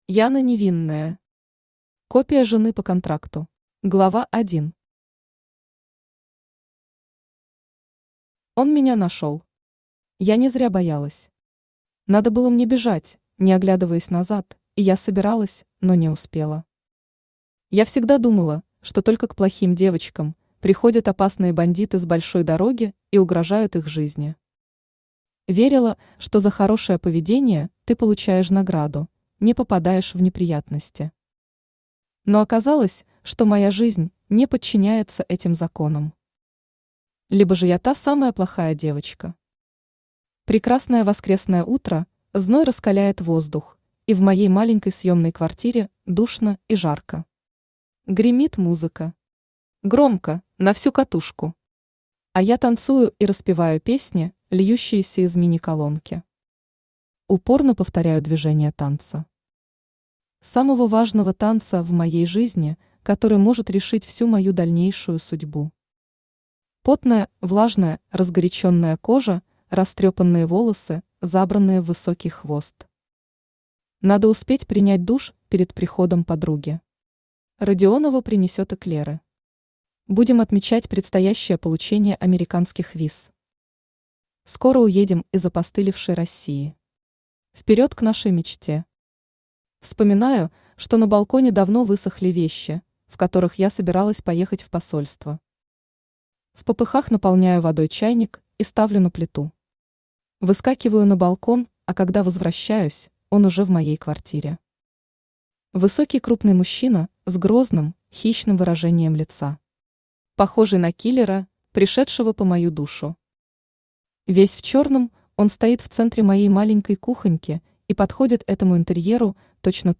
Аудиокнига Копия жены по контракту | Библиотека аудиокниг
Aудиокнига Копия жены по контракту Автор Яна Невинная Читает аудиокнигу Авточтец ЛитРес.